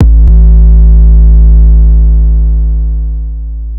TCE 76 TM SIZZ 808.wav